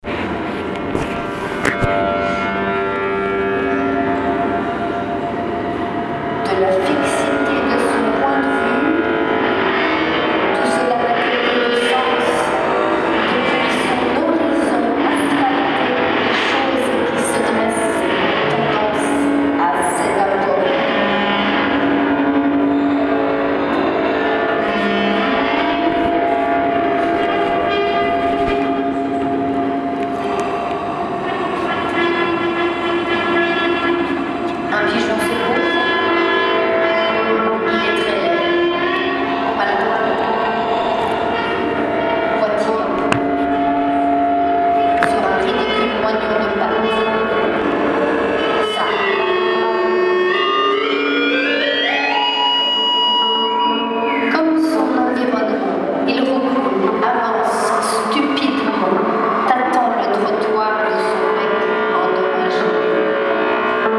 Deux performances sonores et chorégraphiques ont été réalisées à deux moments où la luminosité oscillait, à 19 :00 puis à 21 :00.